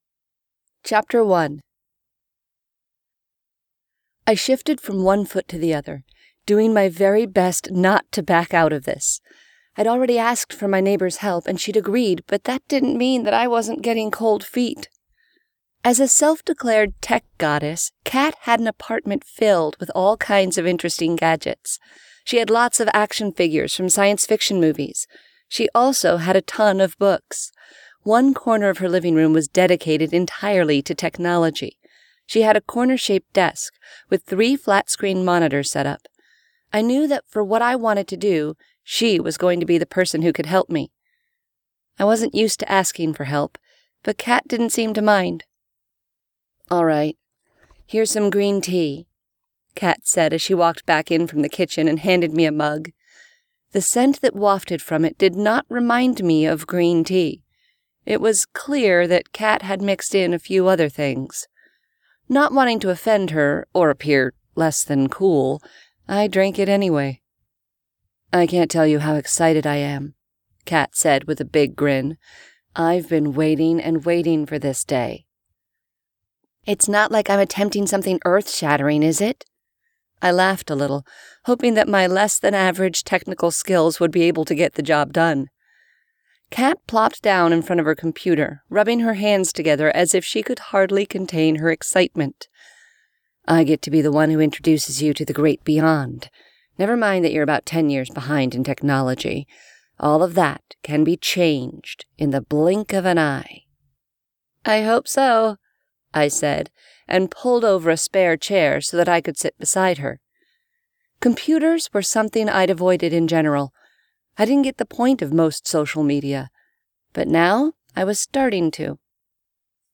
( Audible , Amazon and iTunes ) Click here to find out how to get this audiobook for FREE!